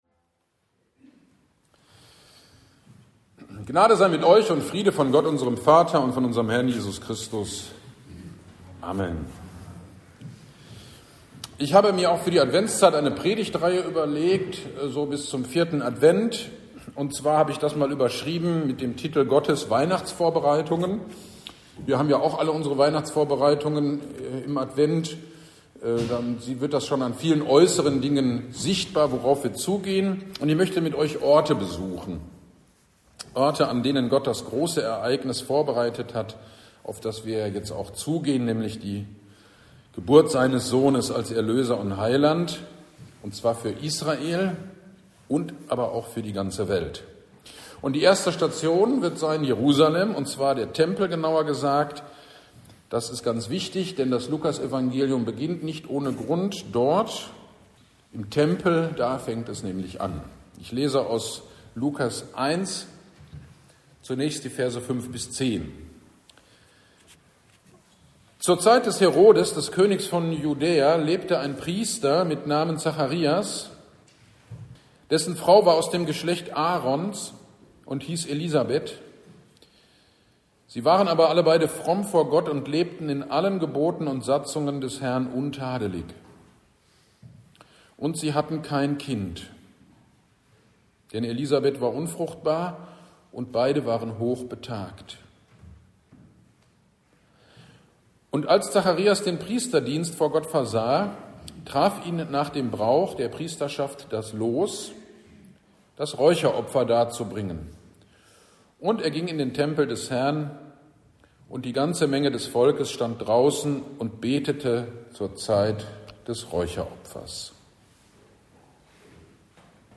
GD am 1. Advent (30.11.25) Predigt zu Lukas 1, 5-24a